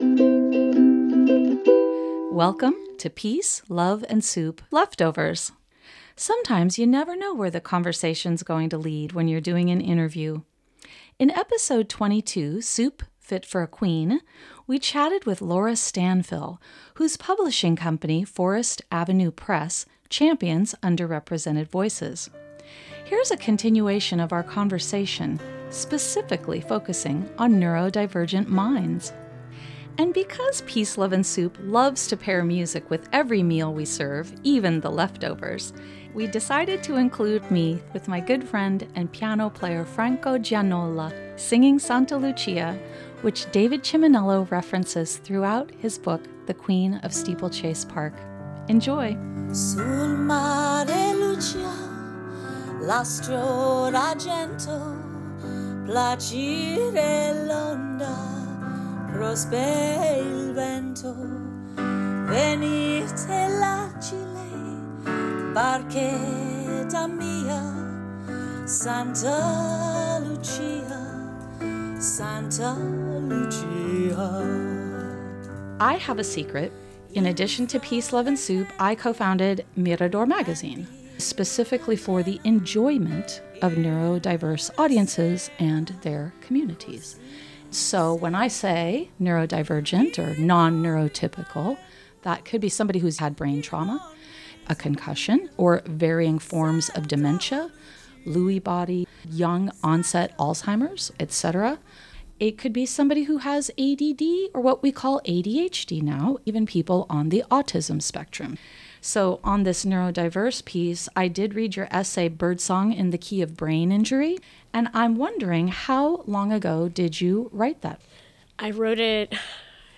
on piano